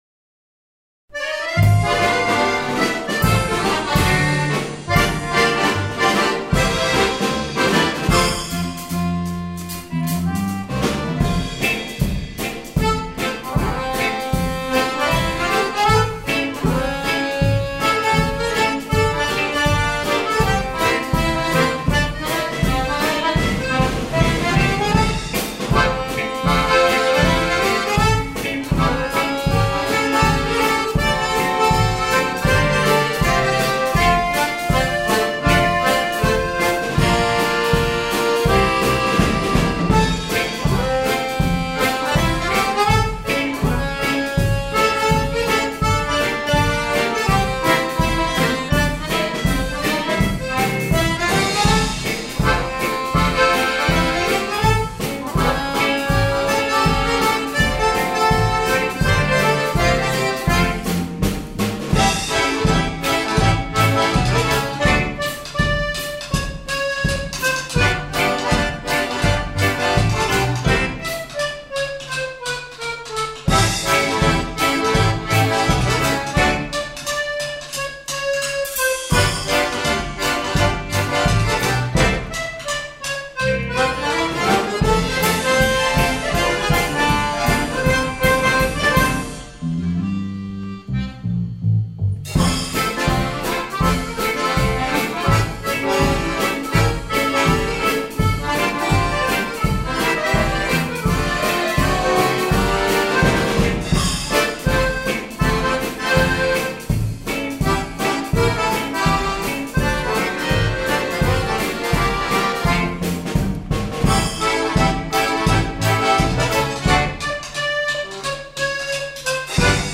2010 – Akkordeonorchester Neustadt bei Coburg e. V.